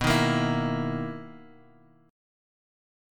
BM11 Chord
Listen to BM11 strummed